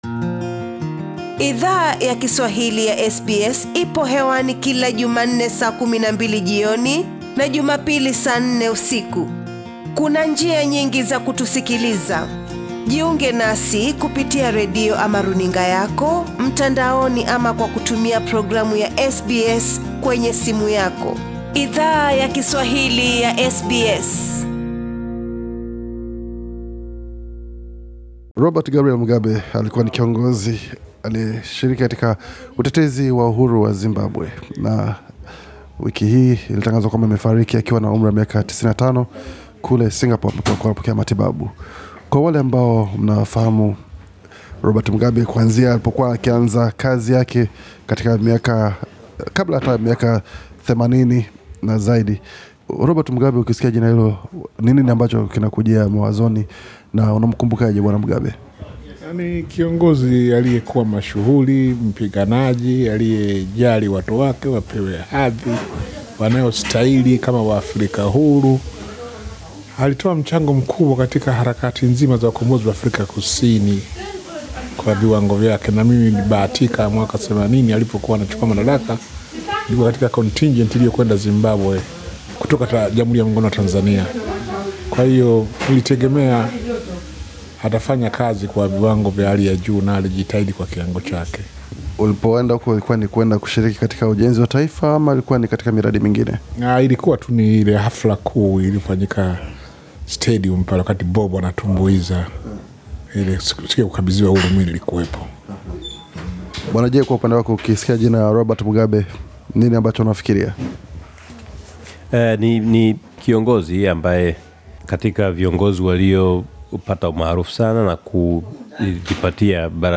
Baadhi ya wanachama wa jamii yawa Australia wenye asili ya Afrika, walichangia maoni yao na Idhaa ya Kiswahili ya SBS, kuhusu maisha na kifo cha Robert Mugabe.